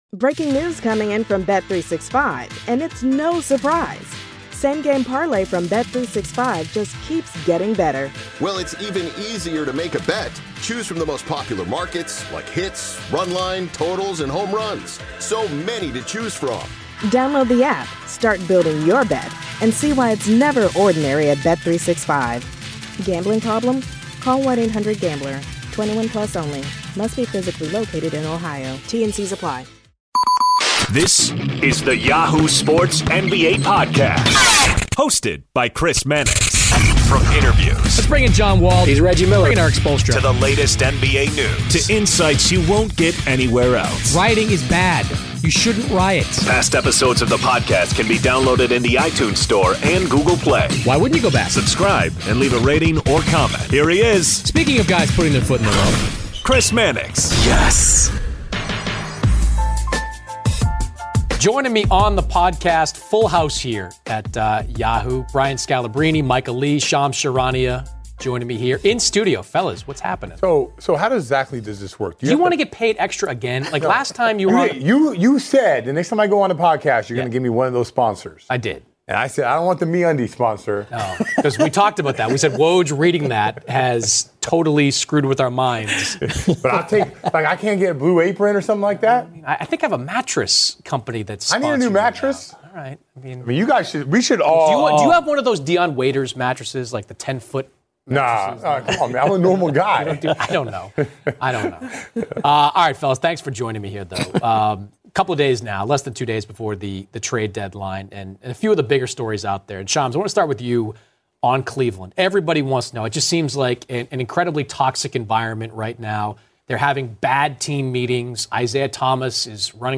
Yahoo! Sports NBA writers and insiders round table
Joining Chris Mannix of Yahoo Sports this week is a round table of NBA writers and insiders.